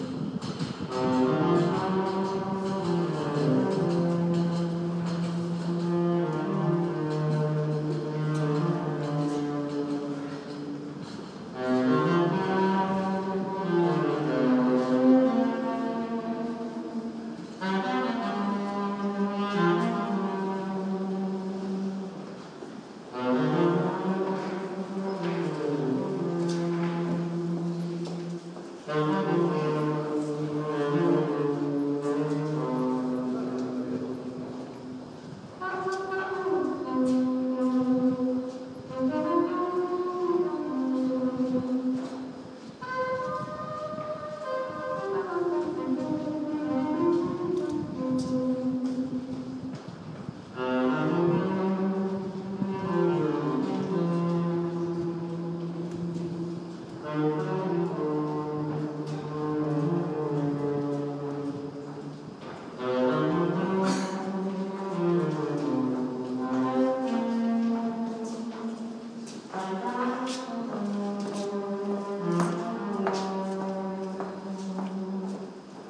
Surreal sax in the Moscow metro, today